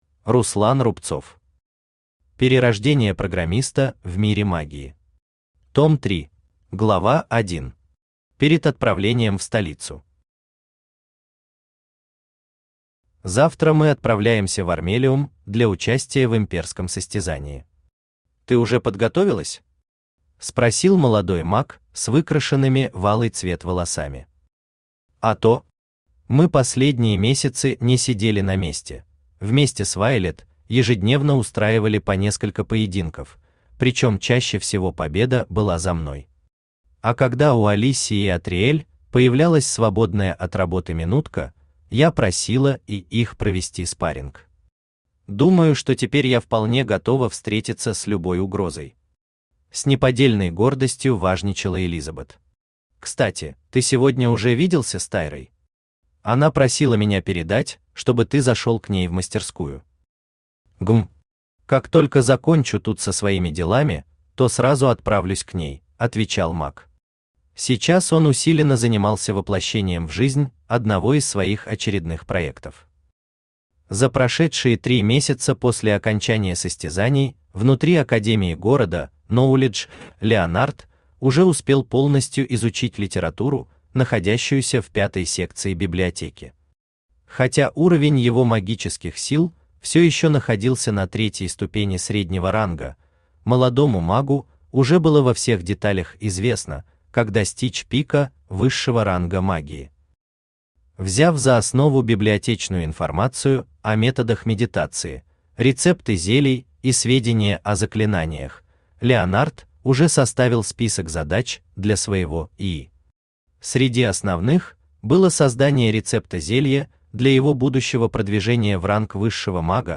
Аудиокнига Перерождение Программиста в Мире Магии. Том 3 | Библиотека аудиокниг
Том 3 Автор Руслан Рубцов Читает аудиокнигу Авточтец ЛитРес.